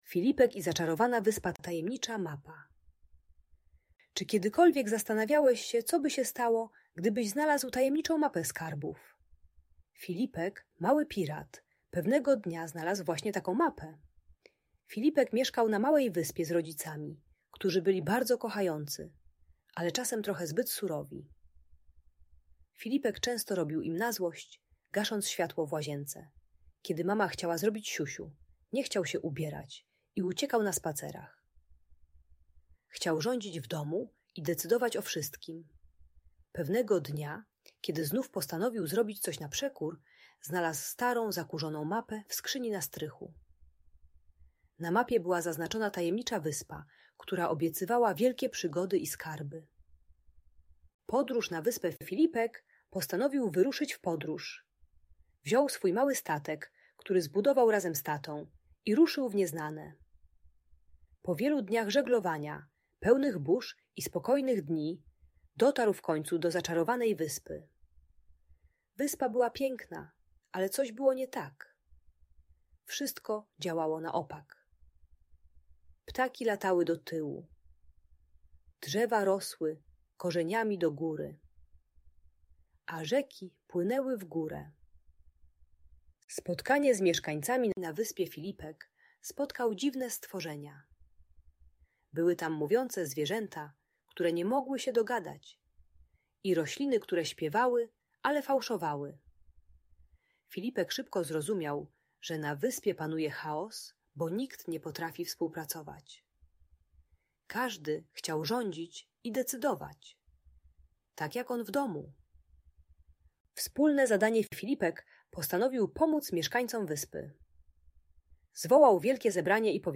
Filipek i Zaczarowana Wyspa - Niepokojące zachowania | Audiobajka